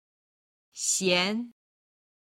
「鲜(xiān) 素材自体の旨味がよい」は一声、「咸(xián)しょっぱい、塩辛い」は二声。 今日の振り返り音源でその差を確認してみましょう！